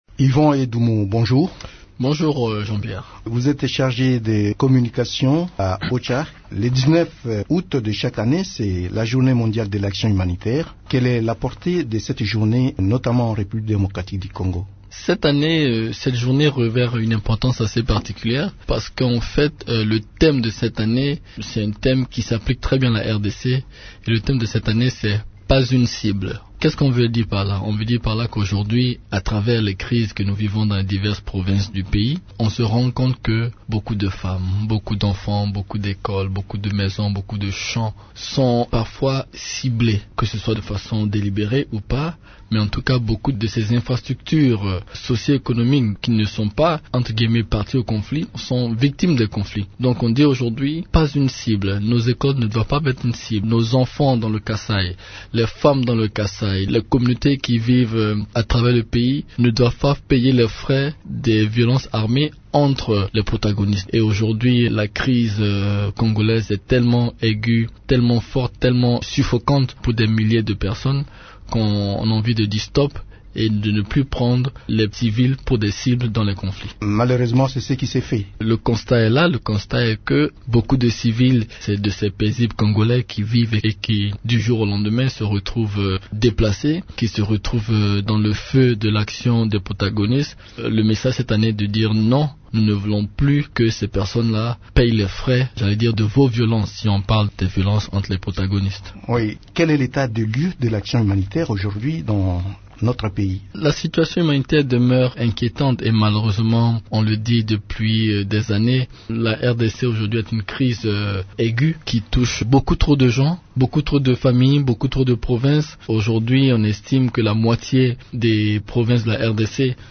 s'entretient avec